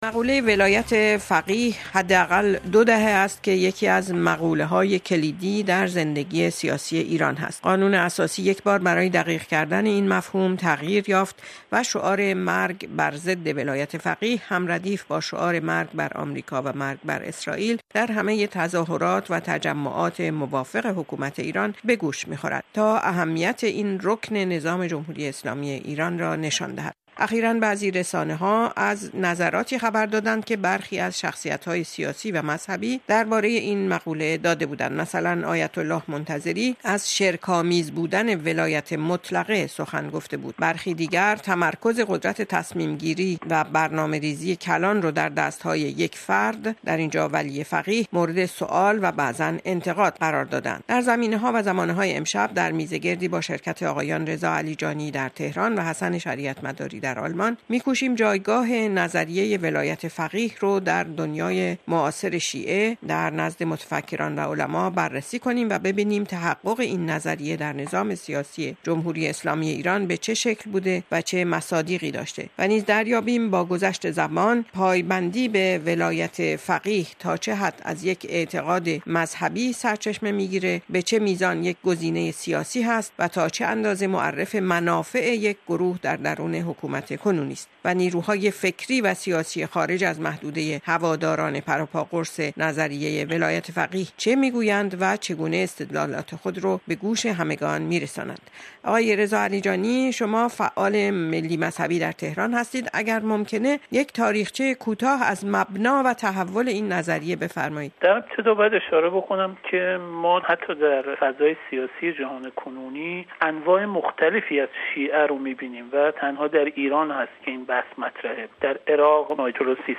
میزگرد رادیوئی را بشنوید